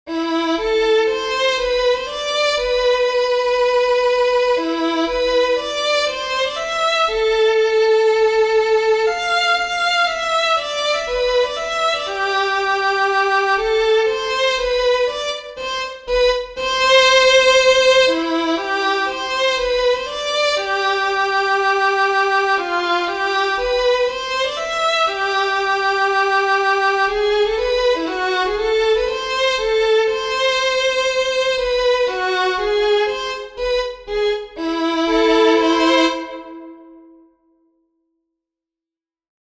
Just been exercising my mind on a bit of simple music theory - and composed this little 24 bar piece which starts off in Amin, modulates to Cmaj and back to Amin at the end.
It's just an "intellectual exercise" really - and I haven't actually played it - what's here is the synthesized version.